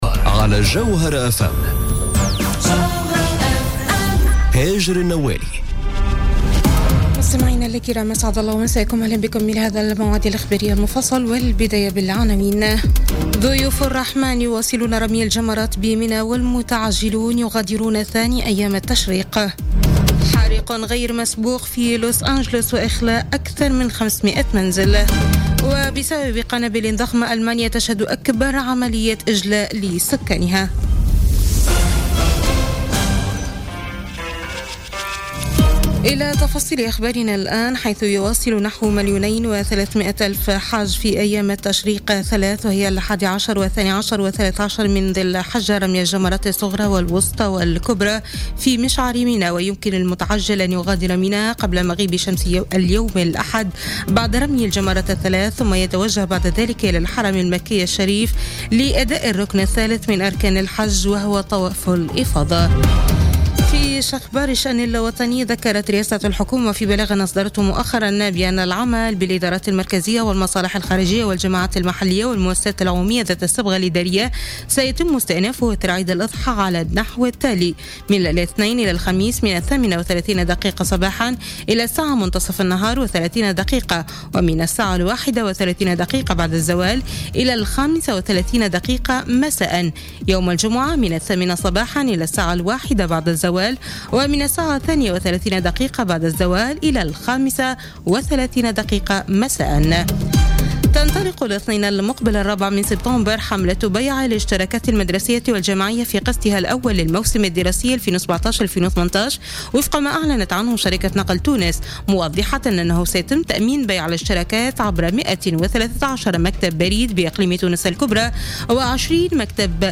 نشرة أخبار منتصف الليل ليوم الأحد 3 سبتمبر 2017